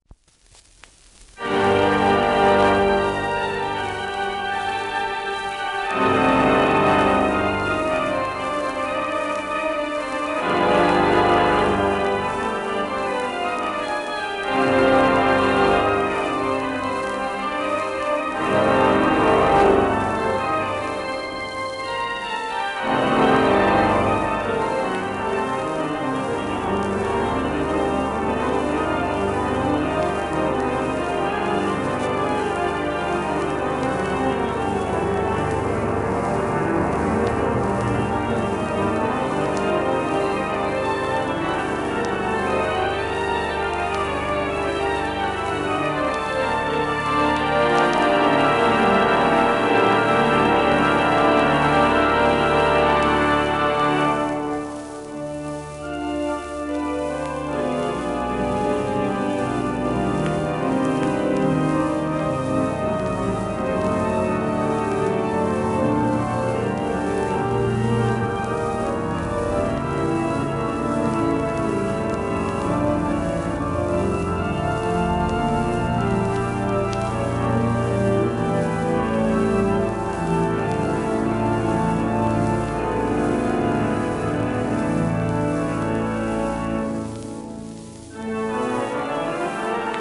1928年、パリ、ノートルダム大聖堂での録音